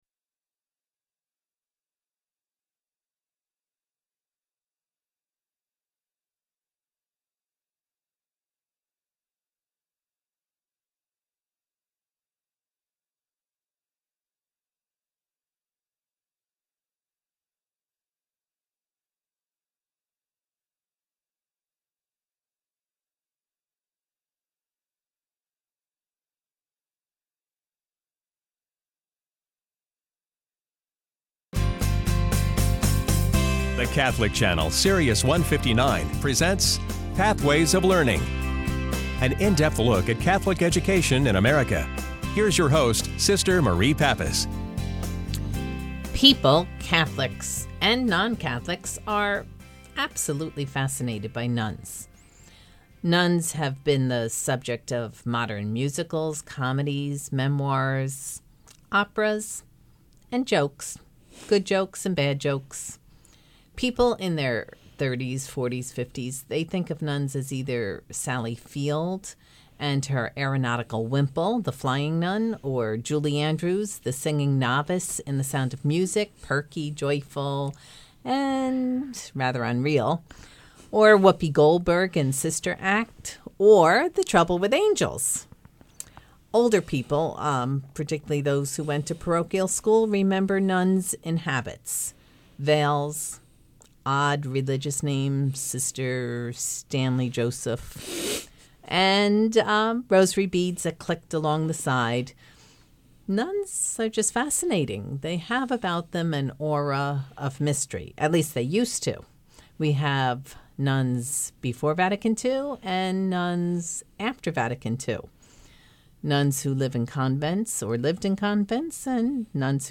01_sirius_radio_interview.mp3